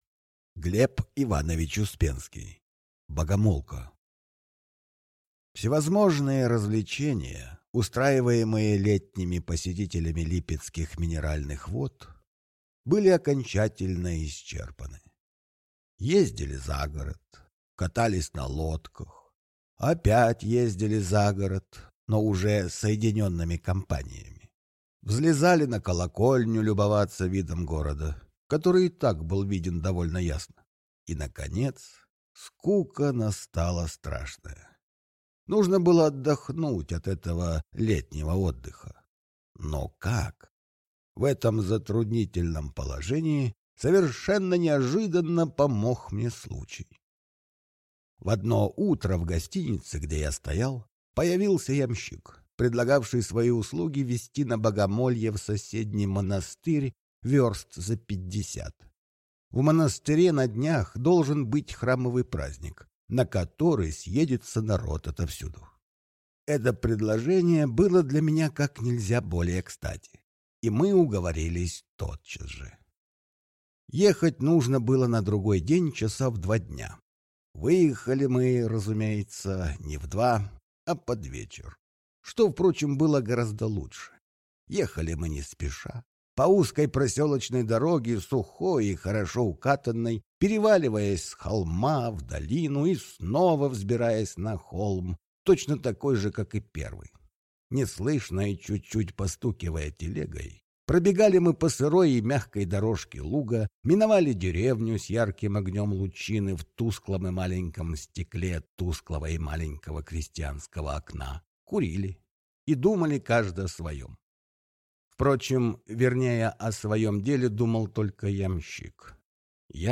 Аудиокнига Богомолка | Библиотека аудиокниг